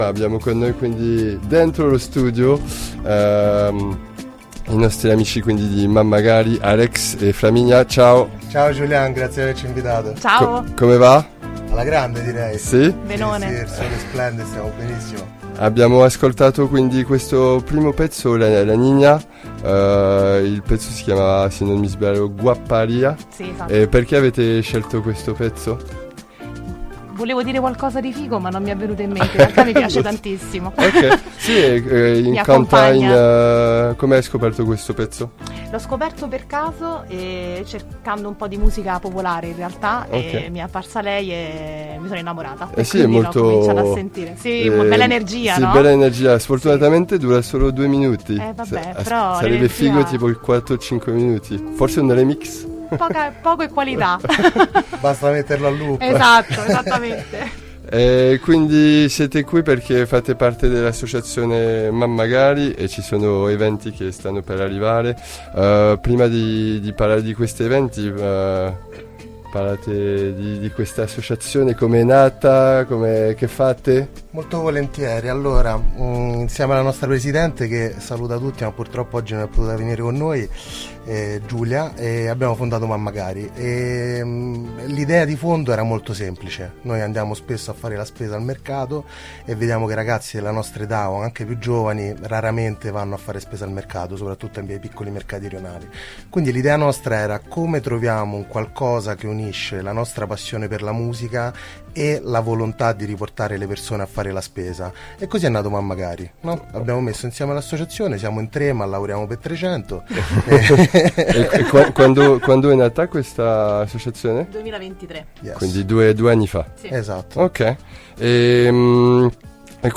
groovy-times-intervista-mammagari.mp3